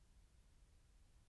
cry